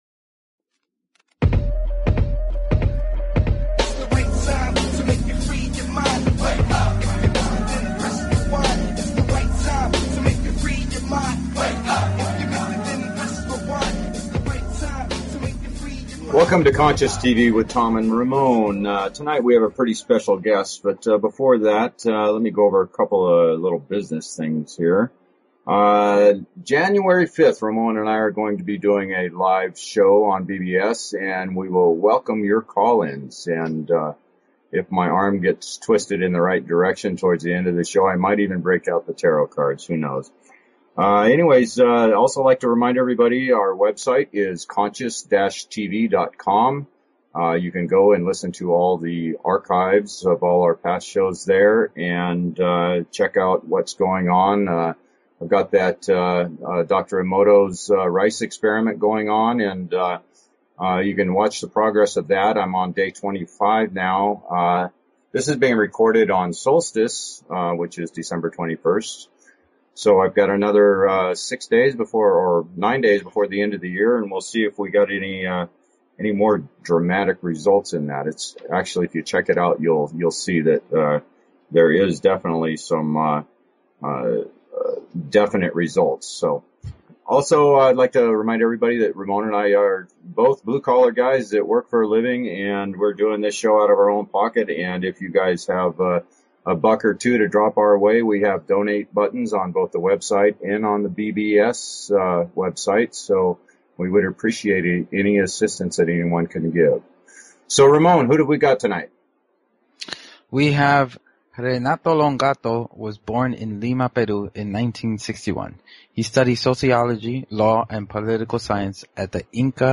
Talk Show Episode, Audio Podcast, The_Hundredth_Monkey_Radio and Courtesy of BBS Radio on , show guests , about , categorized as